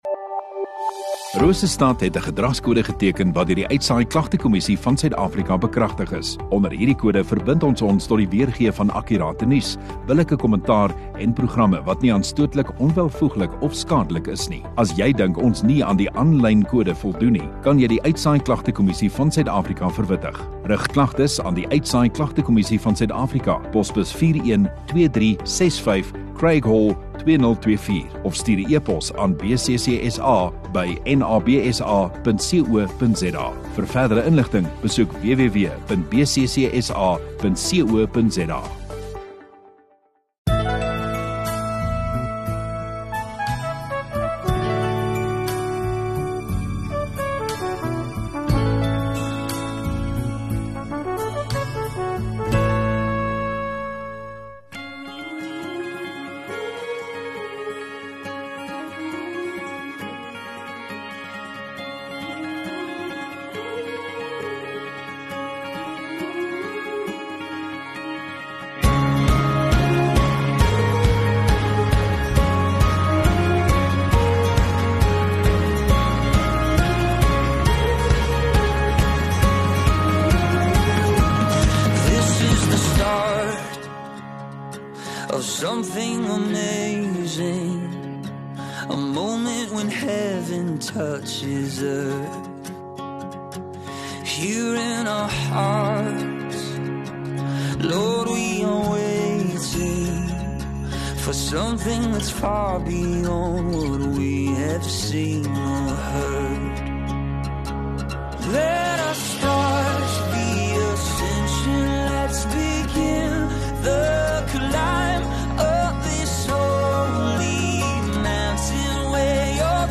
1 Dec Sondagoggend Erediens